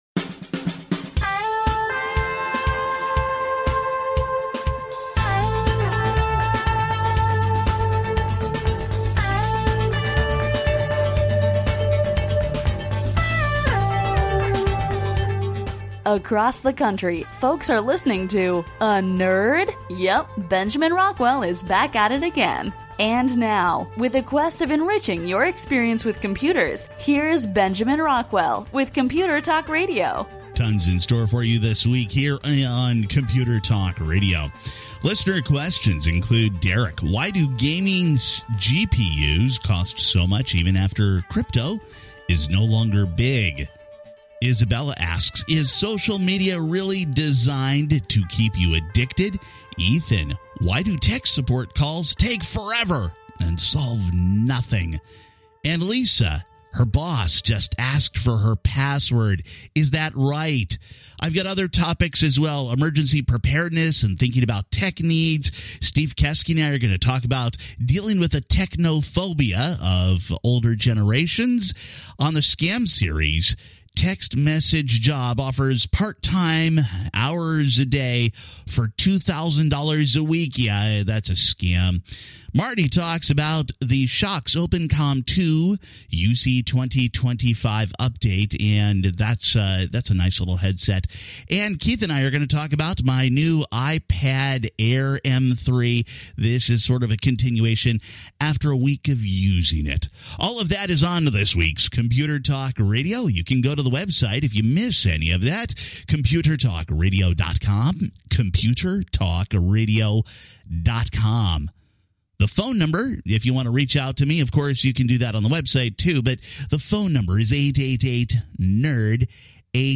Computer Talk Radio is a nationally syndicated broadcast radio program on computers and technology, and how they impact your life.
Talk Radio